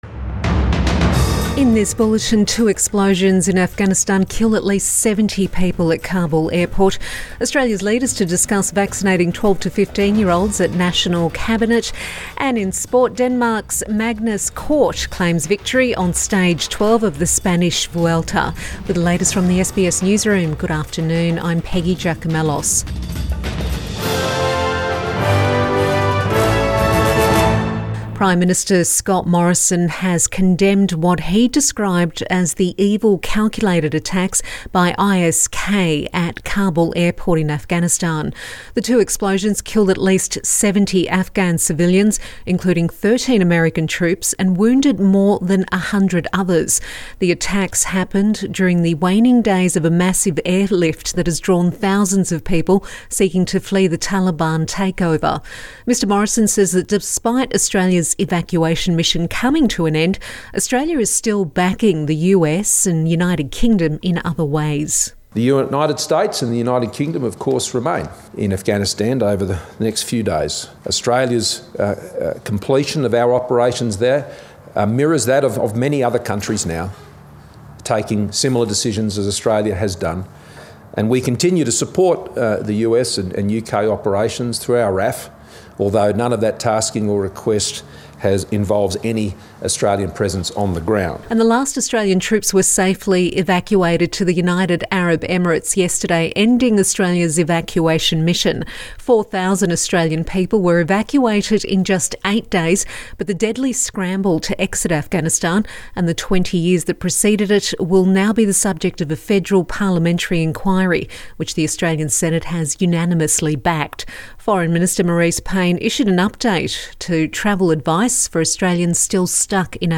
Midday bulletin 27 August 2021